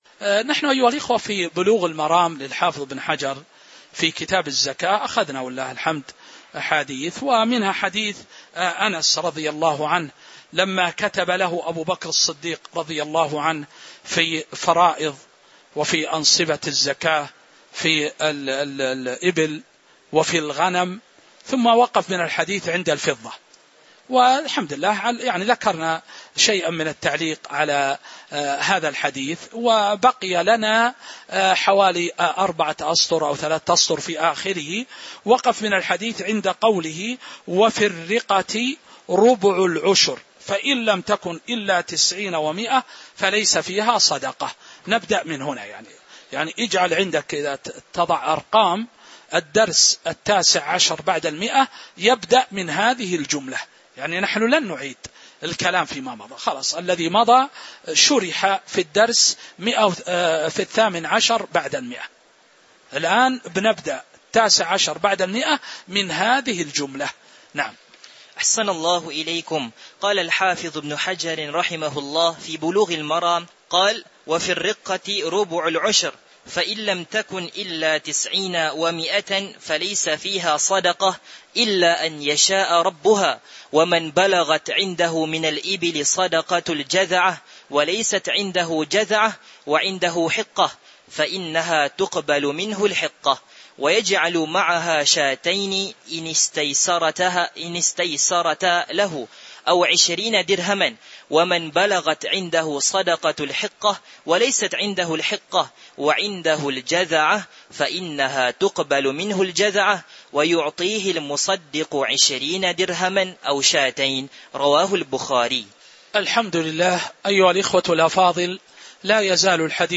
تاريخ النشر ٤ شوال ١٤٤٥ هـ المكان: المسجد النبوي الشيخ